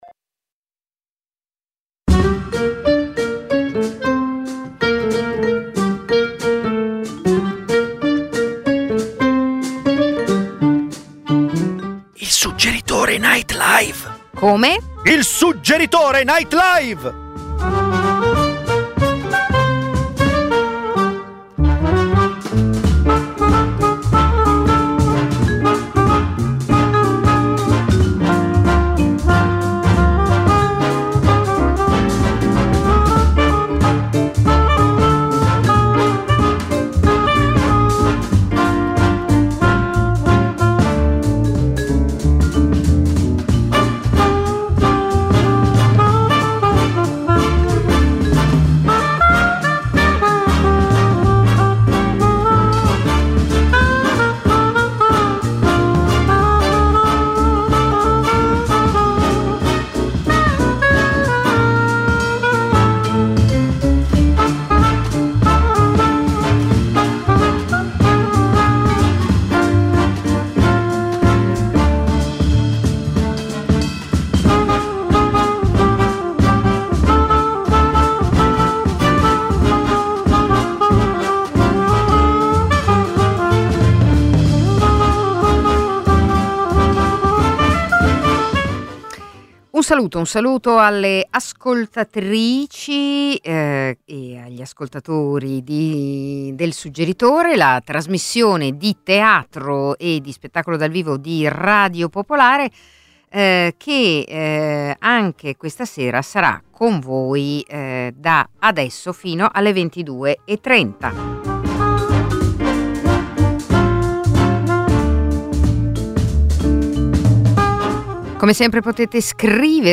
Il Suggeritore Night Live, ogni lunedì dalle 21:30 alle 22:30 dall’Auditorium Demetrio Stratos, è un night talk-show con ospiti dello spettacolo...
Gli ascoltatori possono partecipare come pubblico in studio a partire dalle 21.00. E spesso, il Suggeritore NL vi propone serate speciali di stand up, slam poetry, letture di drammaturgia contemporanea, imprò teatrale.